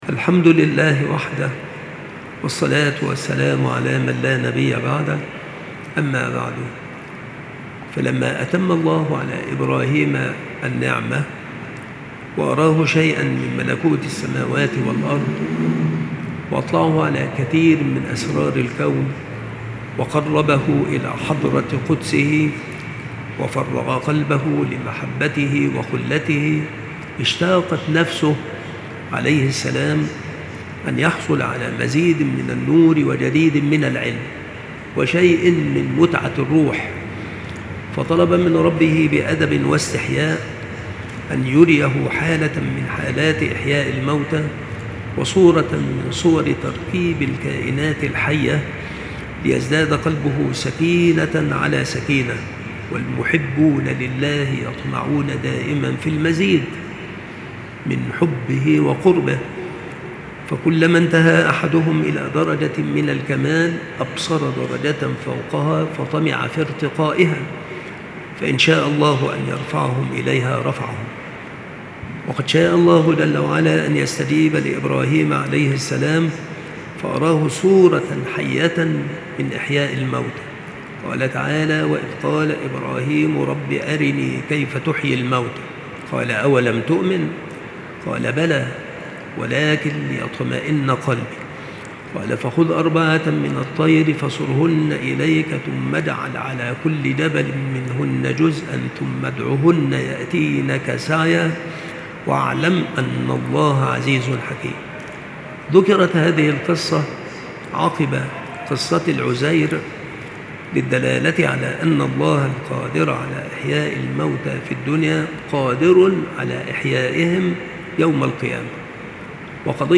• مكان إلقاء هذه المحاضرة : بالمسجد الشرقي - سبك الأحد - أشمون - محافظة المنوفية - مصر